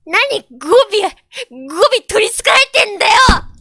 Worms speechbanks
Goaway.wav